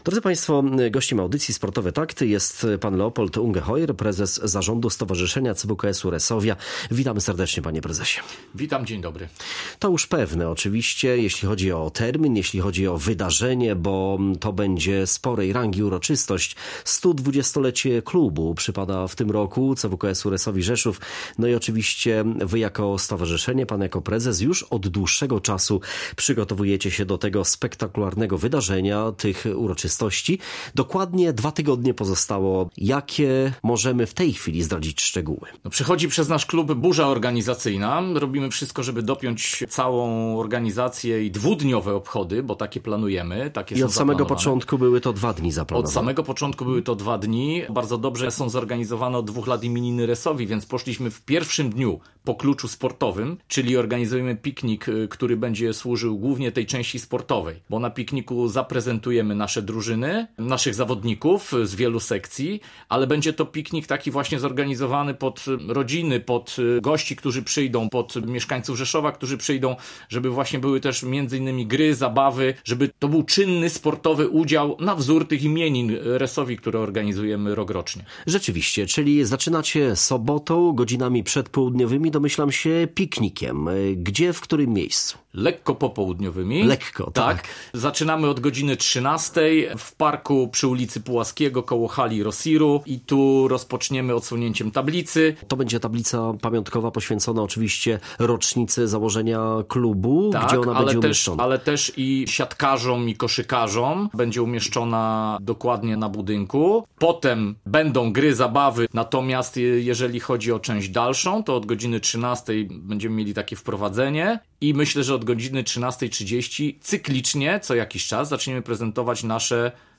W studiu audycji „Sportowe Takty” z naszym gościem rozmawia